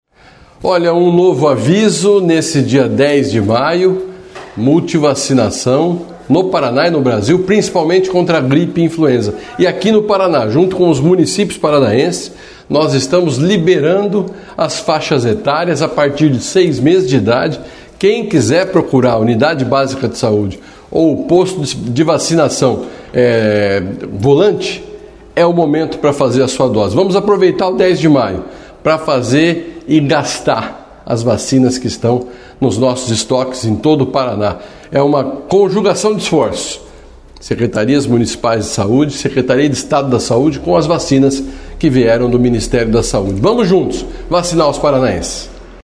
Sonora do secretário Estadual da Saúde, Beto Preto, sobre o Dia D de Multivacinação neste sábado